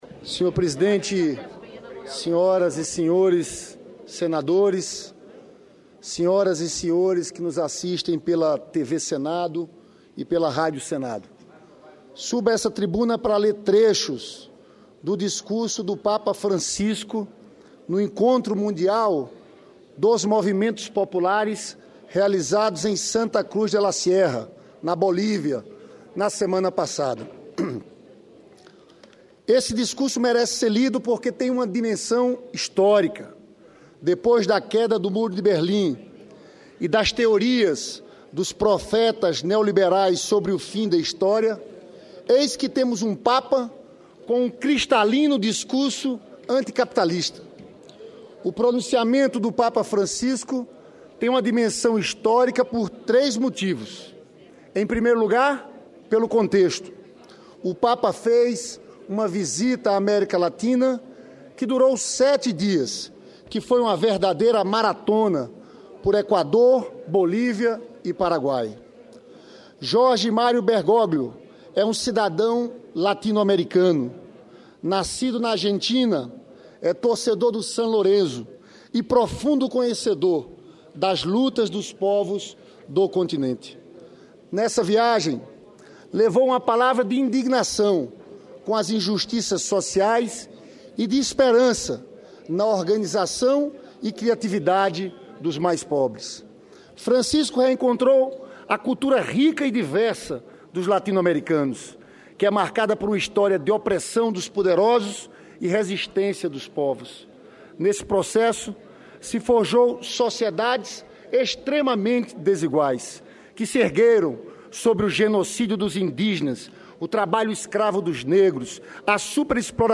Lindbergh lê discurso que Papa Francisco fez na Bolívia
Plenário